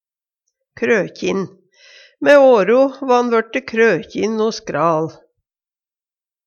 krøkjin - Numedalsmål (en-US)